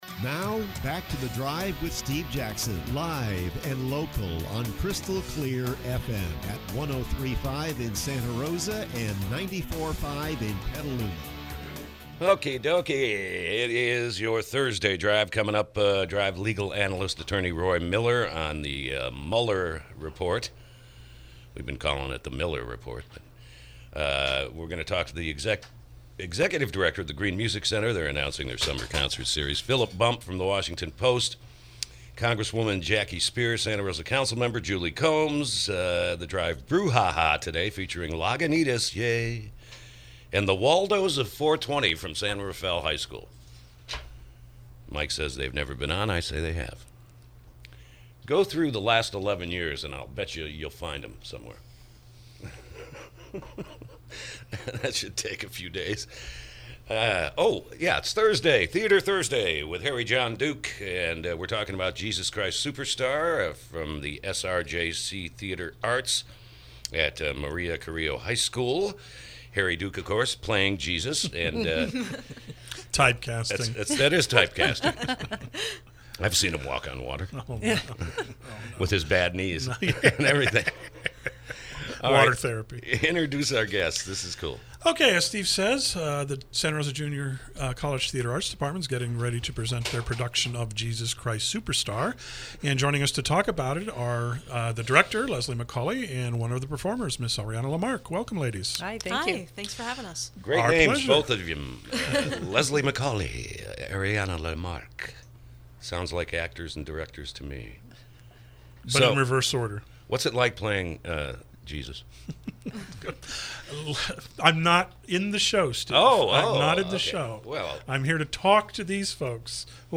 KSRO Interview – “Jesus Christ Superstar”